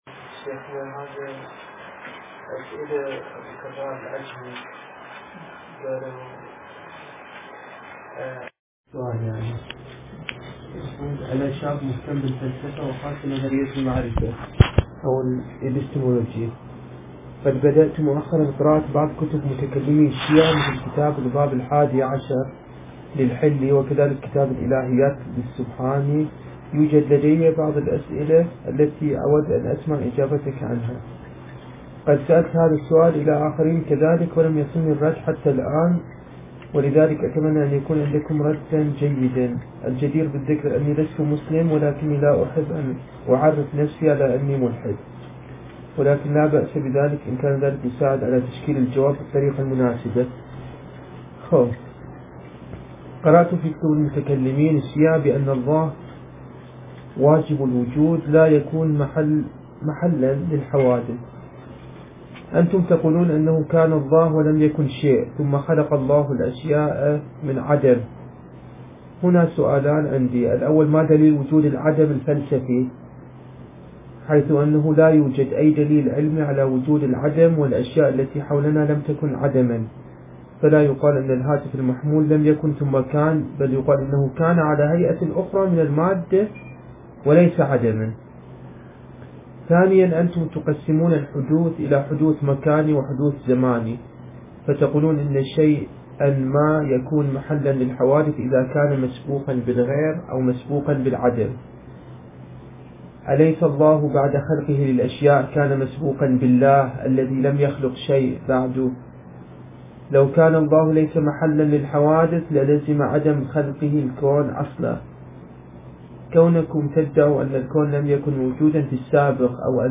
درس ليلة 30 ربيع الأول 1444 هـ (النجف الأشرف)